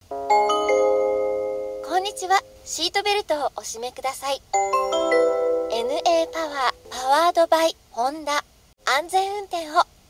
เสียงต้อนรับในรถ Apple CarPlay Sound
เสียงหวานใสสุดน่ารักจากสาวญี่ปุ่นที่จะทำให้บรรยากาศในรถของคุณสดใสและไม่เหมือนใคร เหมาะสำหรับสายแต่งรถและคนชอบความคิวท์สุดๆ
apple-car-play-sound-th-www_tiengdong_com.mp3